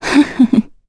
Valance-Vox_Happy1_kr.wav